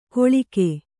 ♪ koḷike